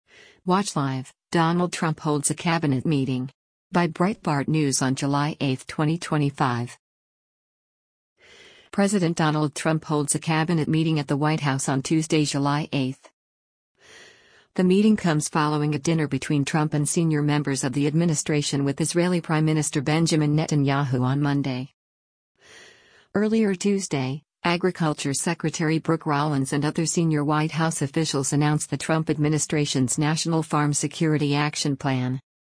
President Donald Trump holds a cabinet meeting at the White House on Tuesday, July 8.